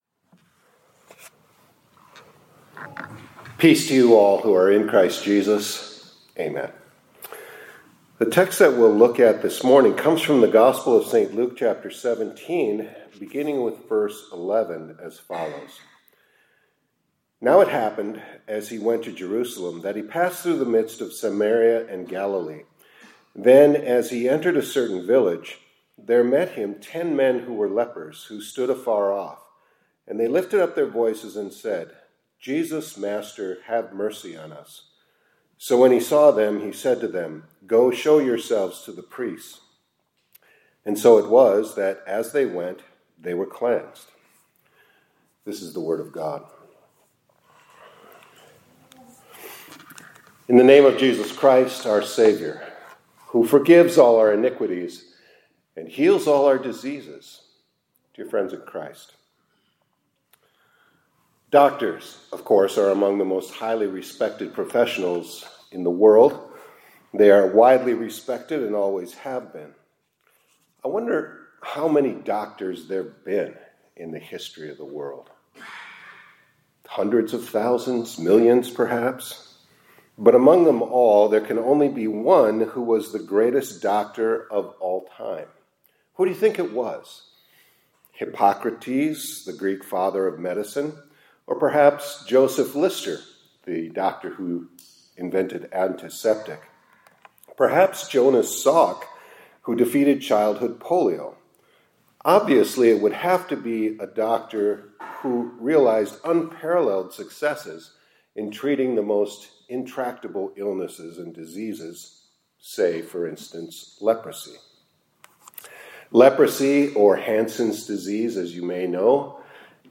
2025-09-08 ILC Chapel — Jesus Is the Greatest Physician